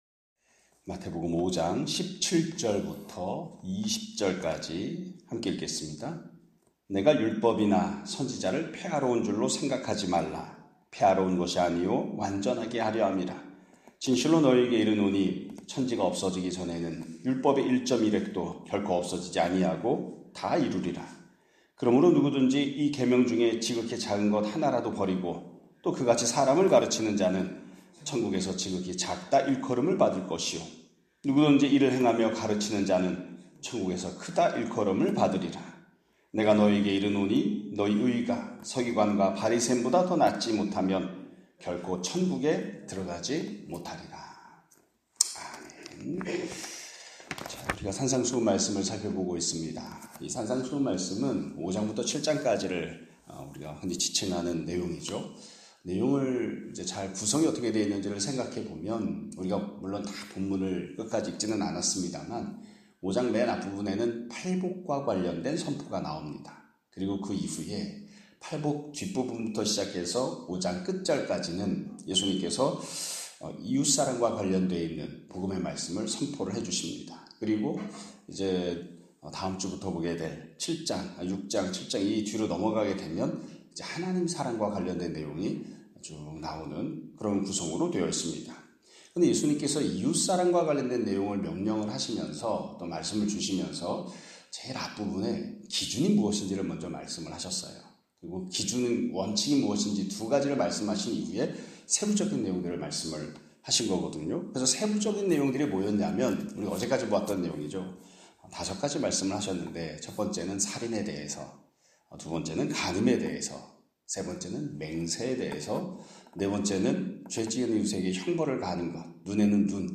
2025년 6월 5일(목 요일) <아침예배> 설교입니다.